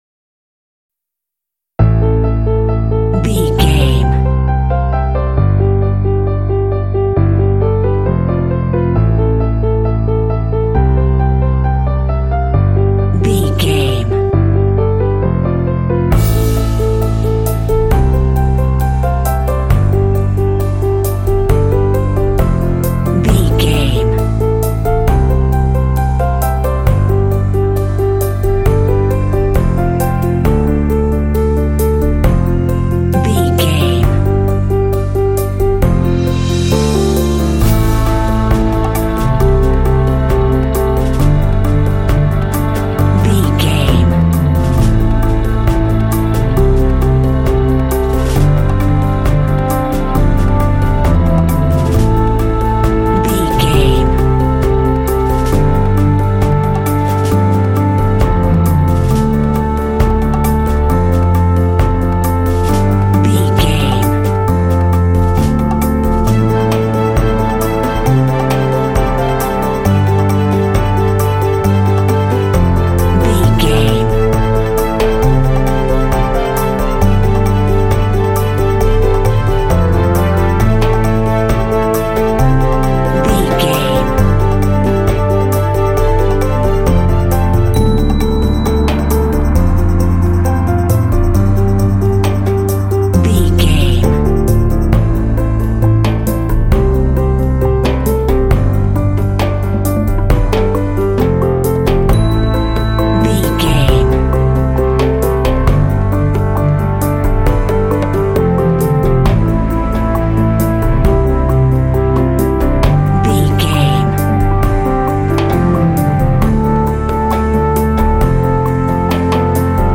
Epic / Action
Aeolian/Minor
tension
elegant
repetitive
piano
drums
synthesiser
horns
strings
orchestra
symphonic rock
cinematic